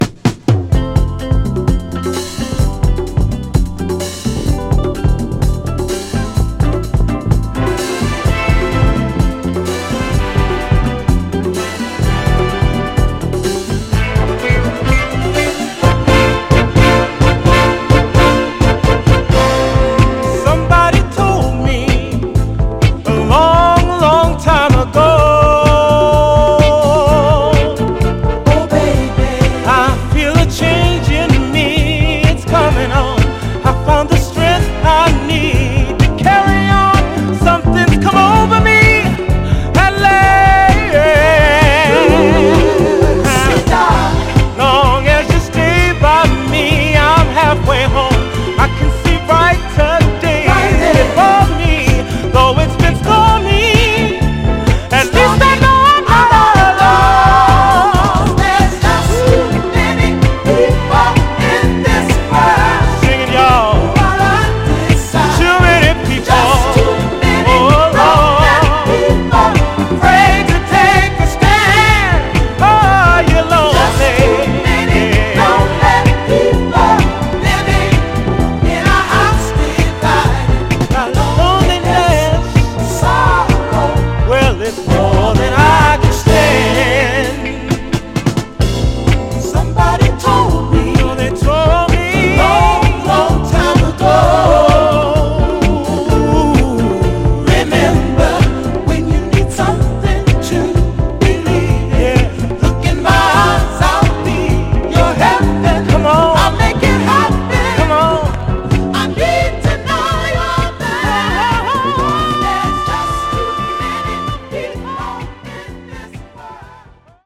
ここでもクッキリとしたシグマ・サウンド爆発で、ディスコ〜ガラージ方面からの支持も熱いヴァージョンです。
細かい線キズ箇所あり、小さくプチっと鳴りますが、それ以外はグロスがありプレイ良好です。
※試聴音源は実際にお送りする商品から録音したものです※